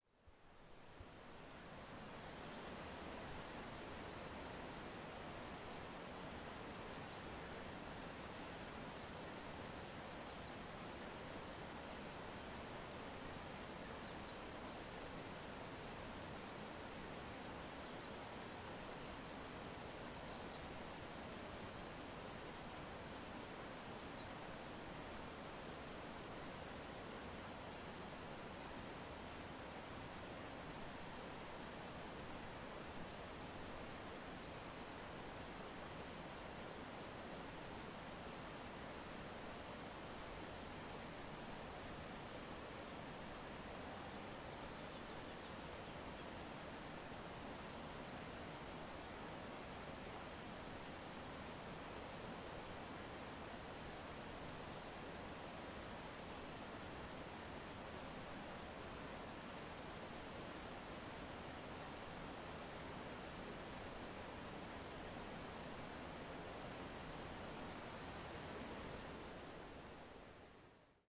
描述：酒店接待处：人们在电话中交谈，门，背景音乐，运动，脚步声。在隆达（西班牙马拉加）用Zoom H4N./pgt录制。 酒店接待处：人们在打电话，门，背景音乐，运动，脚步声。在隆达（西班牙马拉加）用Zoom H4N.
标签： 西班牙 马拉加 西班牙安达卢西亚 内饰 前台 安达卢西亚 recepcion 隆达 酒店 室内
声道立体声